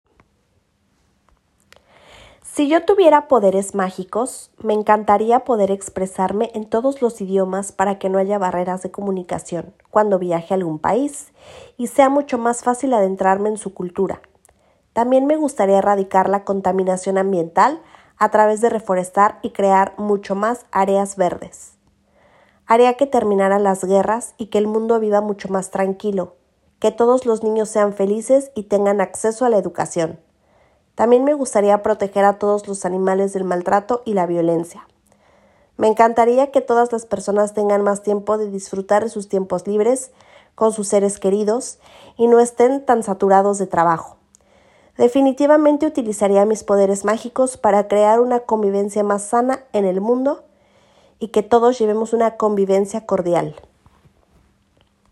Vous trouverez dans cette nouvelle rubrique de courts enregistrements réalisés par les assistants d’espagnol nommés dans notre académie, classés par thèmes et niveau du CECRL.